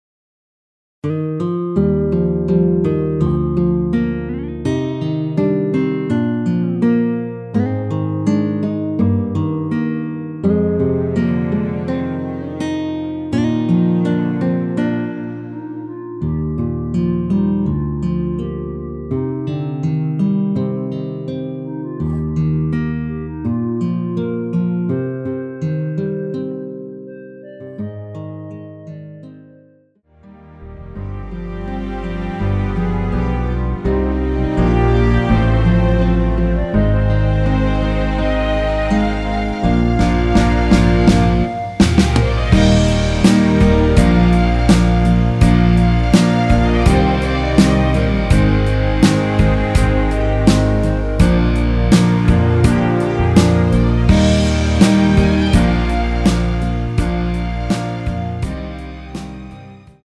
원키에서 (-3)내린 멜로디 포함된 MR 입니다.(미리듣기 참조)
Eb
앞부분30초, 뒷부분30초씩 편집해서 올려 드리고 있습니다.
중간에 음이 끈어지고 다시 나오는 이유는